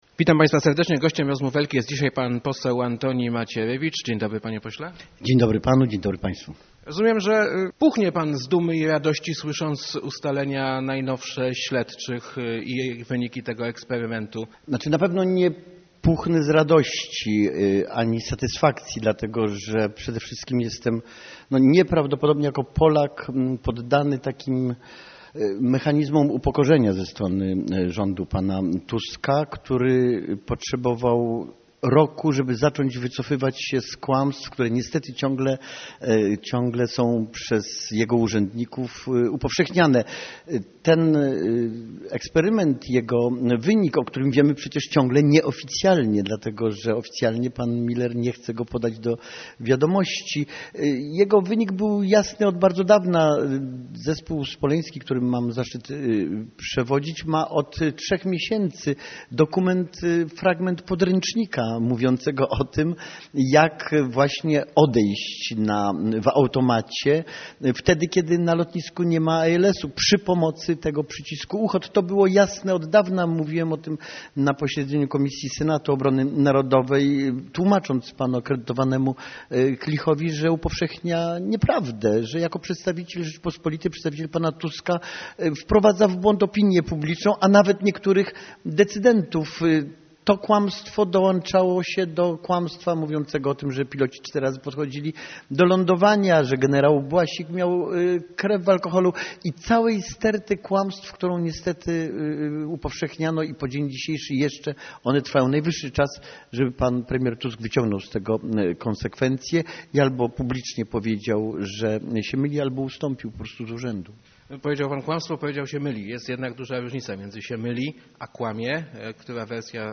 Przyczyną katastrofy w Smoleńsku było działanie osób trzecich - mówił w Rozmowach Elki poseł PiS Antoni Macierewicz, stojący na czele parlamentarnego zespołu zajmującego się wyjaśnianiem tragedii.